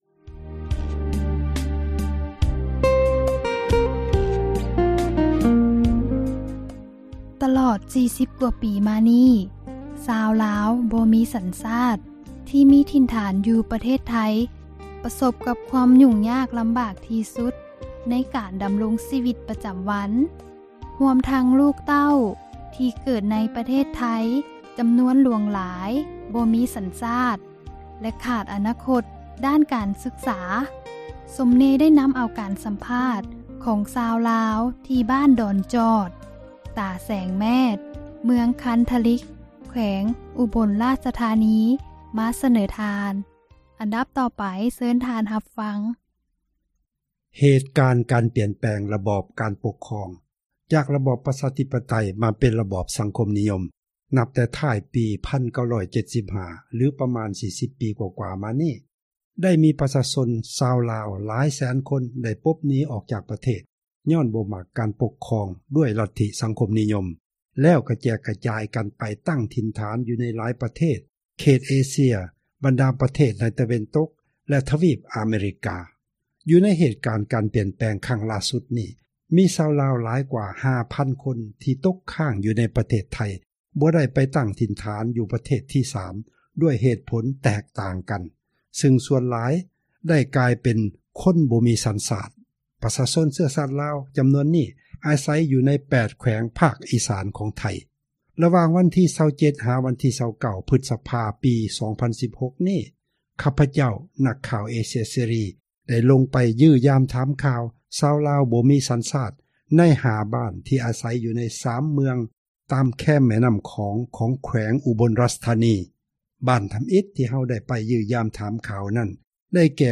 ການສຳພາດ ຊາວລາວ ທີ່ ບ້ານດອນຈອດ ຕາແສງ ແມັດ ເມືອງ ຄັນທະລິກ ແຂວງ ອຸບົນ ຣາຊທານີ